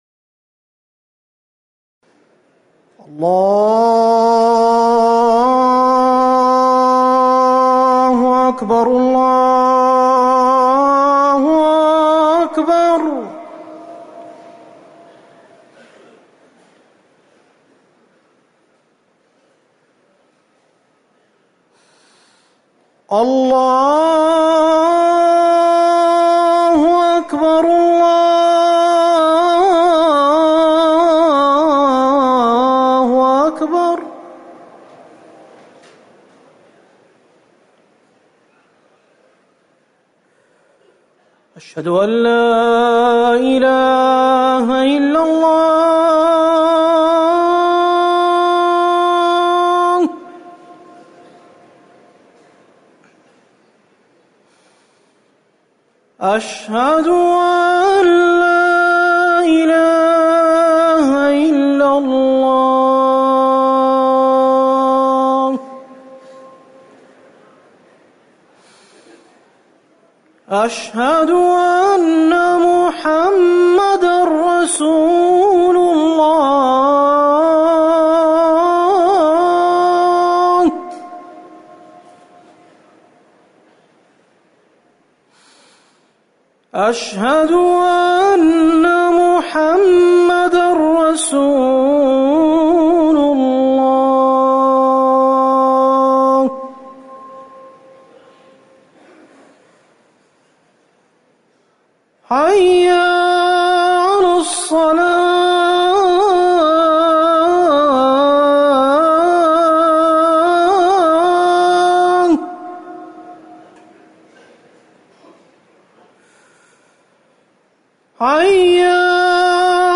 أذان العصر
المكان: المسجد النبوي